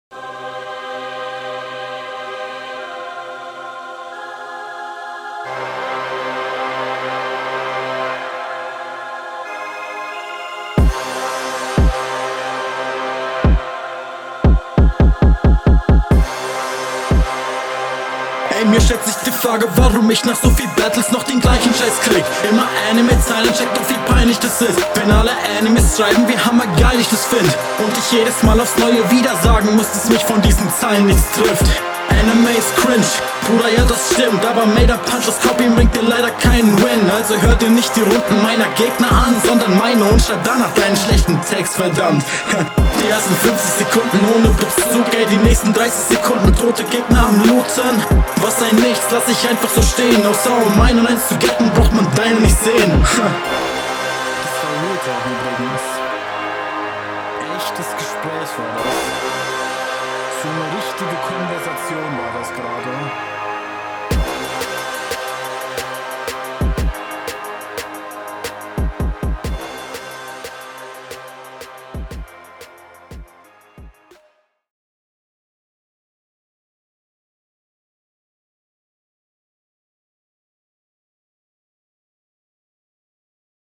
Du gehst leider sehr im beat unter :c